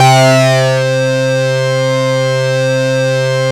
SYNTH LEADS-2 0002.wav